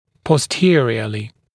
[pɔs’tɪərɪəlɪ][пос’тиэриэли]сзади, кзади; в боковых сегментах зубных дуг